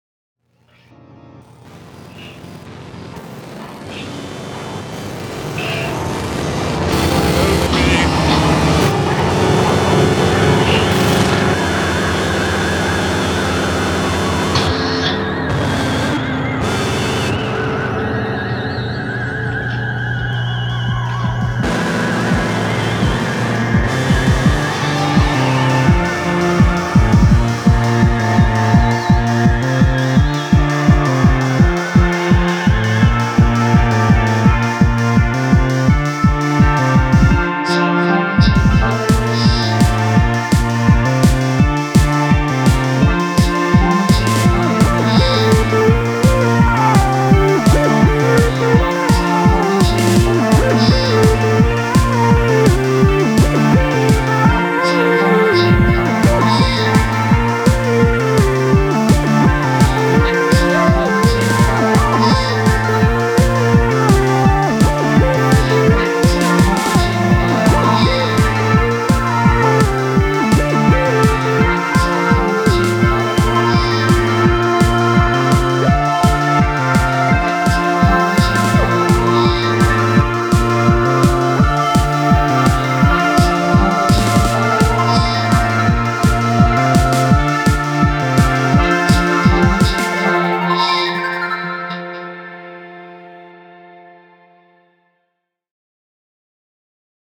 Guitar, Synths, Vocals, Programming & Production
Keyboards, Programming